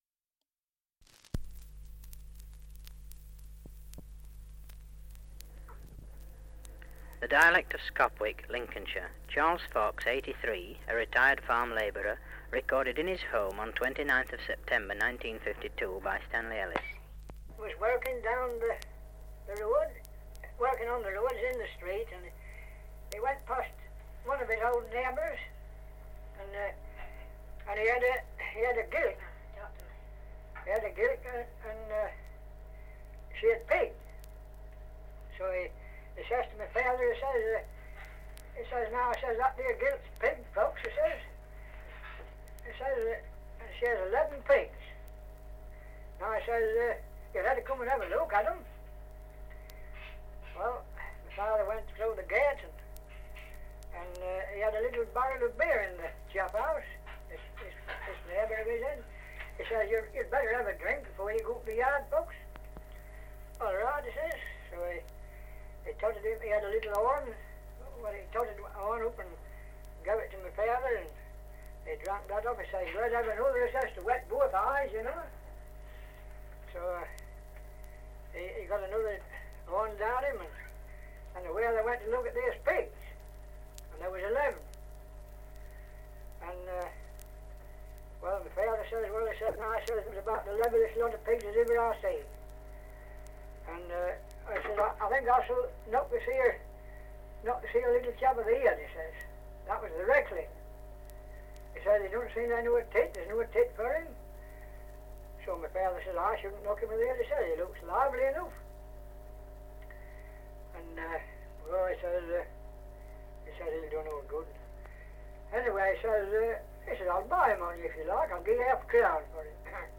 Survey of English Dialects recording in Scopwick, Lincolnshire
78 r.p.m., cellulose nitrate on aluminium